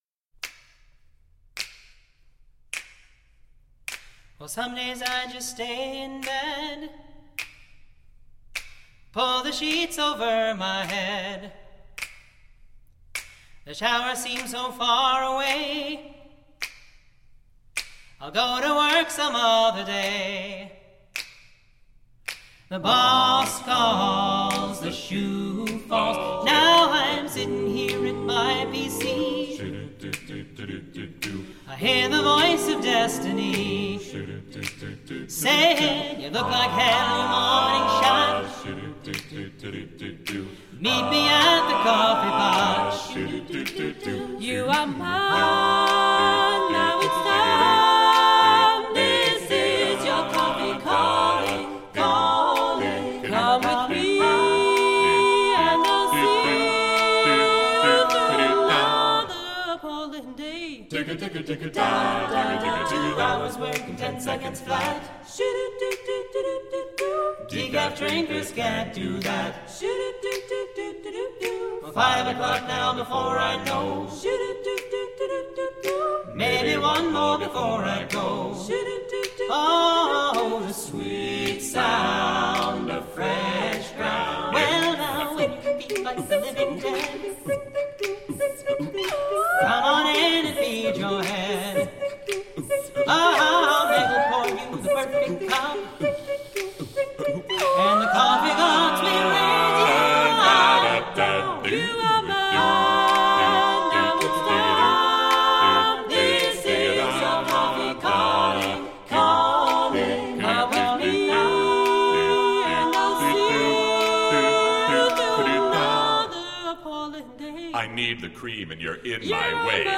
A doo-wop song of praise for the loving cup.
SATTB a cappella
An original doo-wop